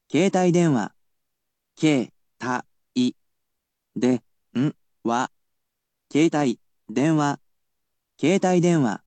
I will also tell you the pronunciation of the word using the latest in technological advancements.